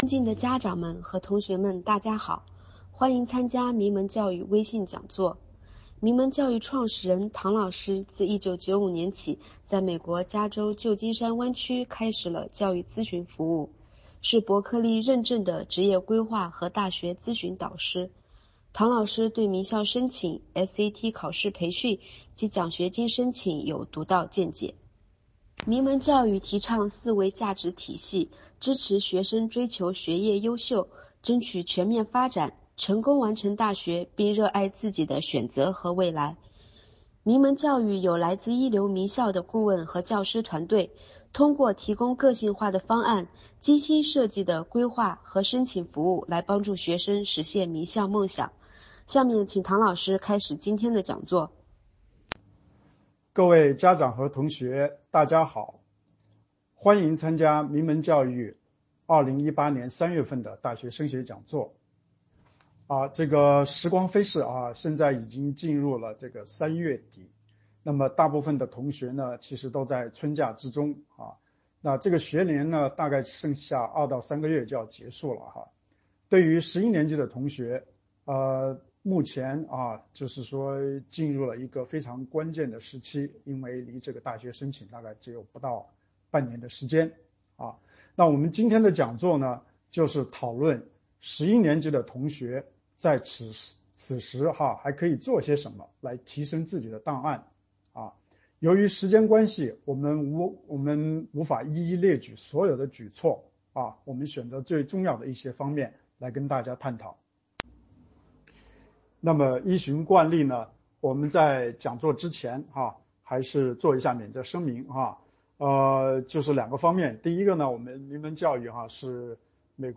0329名门升学讲座实录---大学申请前半年，如何提升自身档案 — 名门教育
名门教育升学讲座上篇-顾问分享.mp3